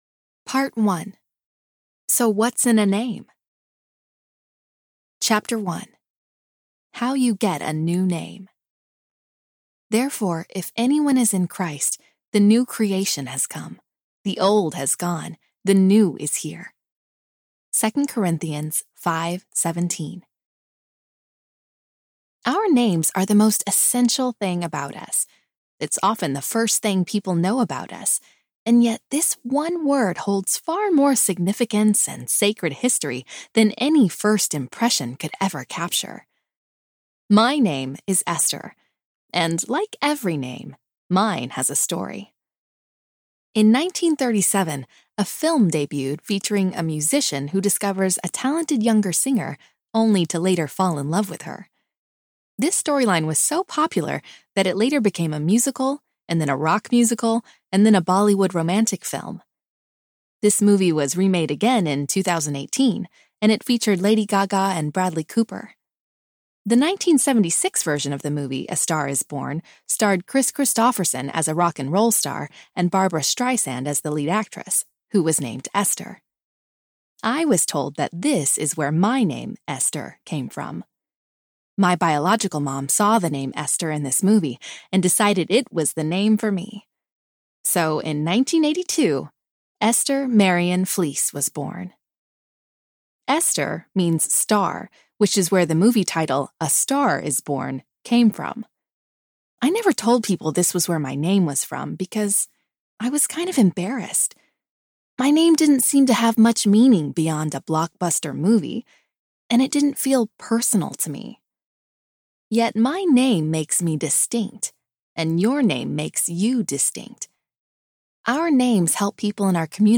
Your New Name Audiobook
5.3 Hrs. – Unabridged